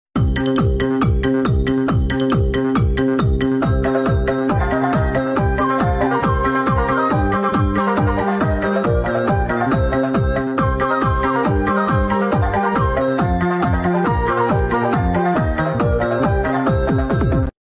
Hồi phục nội lực với âm thanh rung động sâu